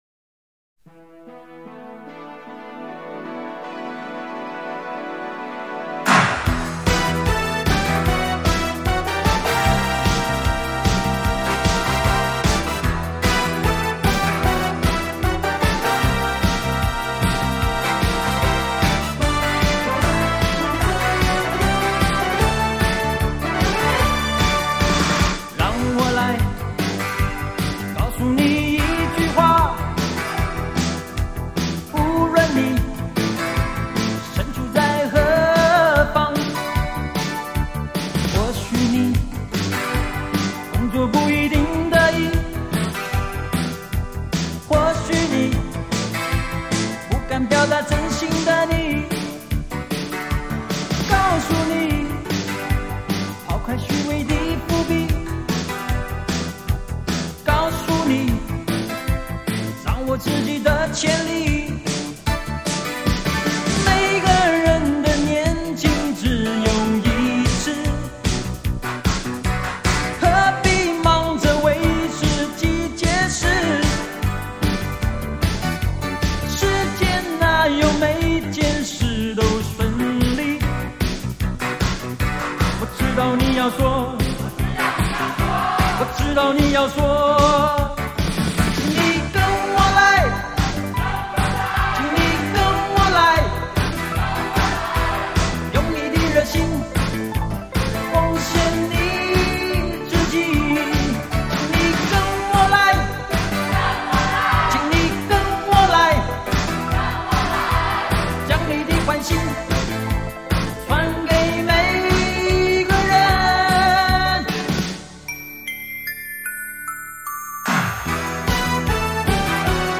散发着浓浓的青春气息 动感十足